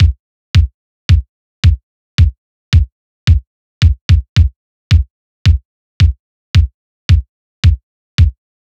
Unison Funk - 9 - 110bpm - Kick.wav